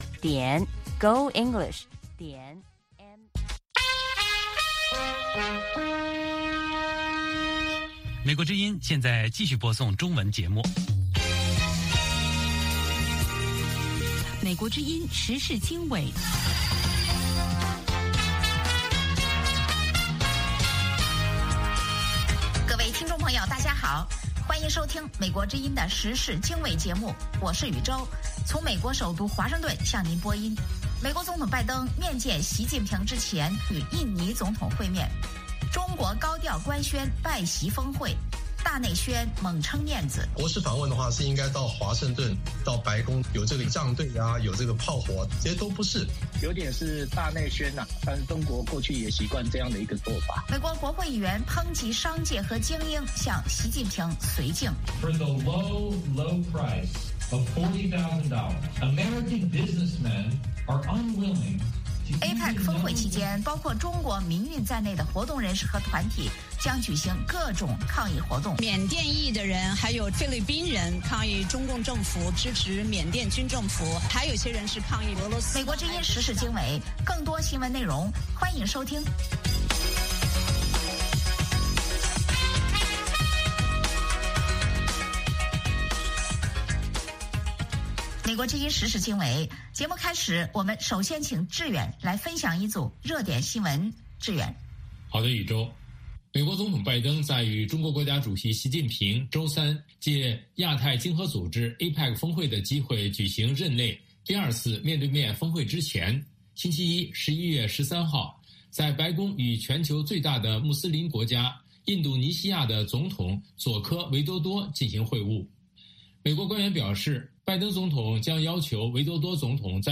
英语教学
美国之音英语教学节目。